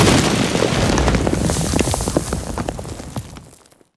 dirt6.ogg